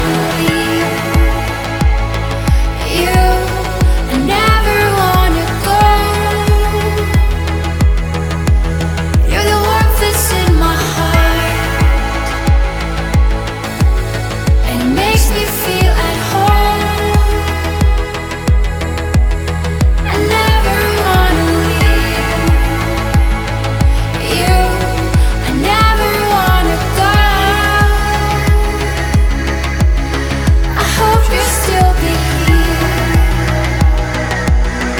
Жанр: Поп музыка / Альтернатива